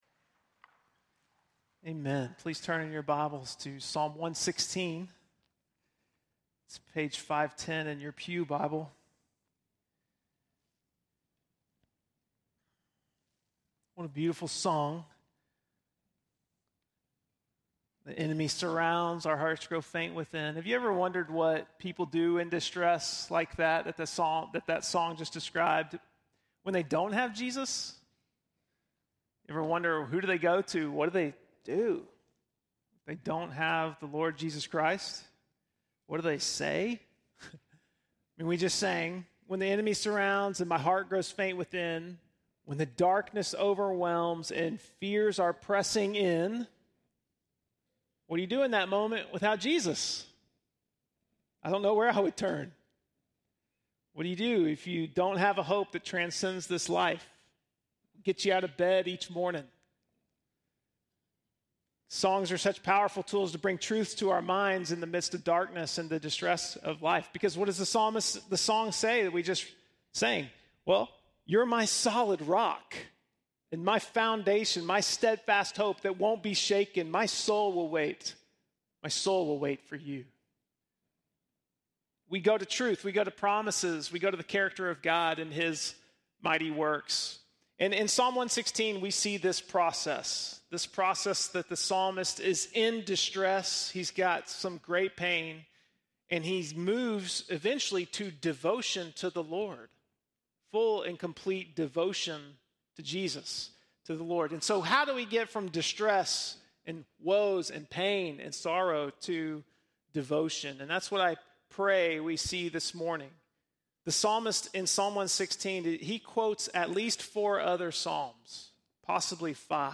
7.16-sermon.mp3